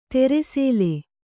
♪ tere sēle